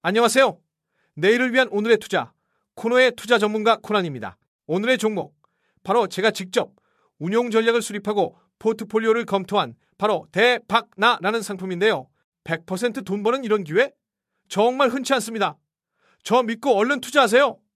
투자사기단이 당신의 목소리를 조작해 투자 허위 정보를 퍼뜨렸습니다.
들려드린 목소리는 모두 AI로 복제된